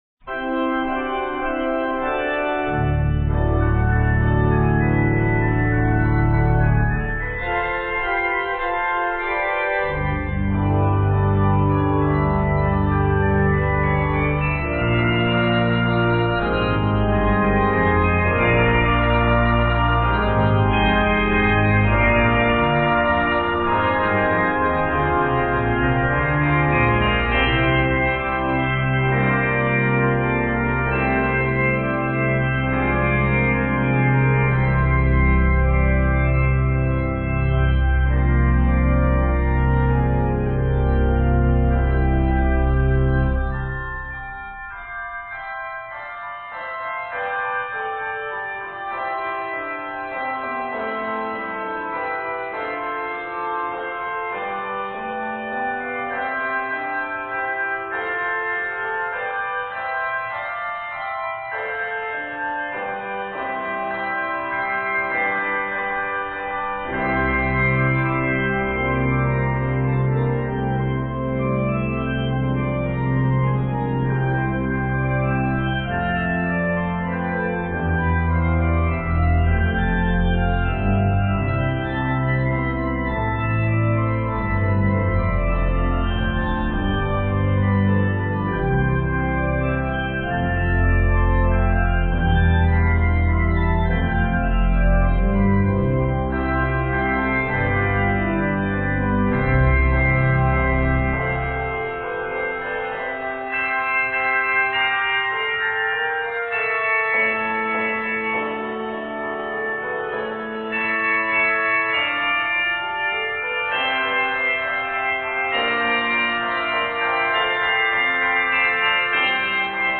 is 119 measures in C Major.